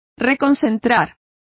Complete with pronunciation of the translation of concentrating.